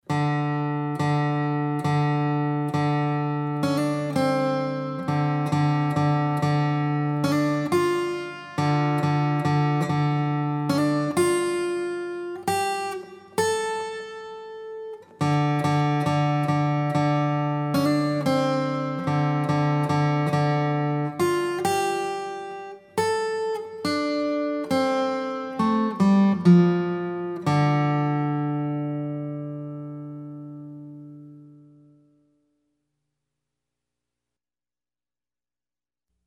Rock Freebies